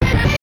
jingles-hit_13.ogg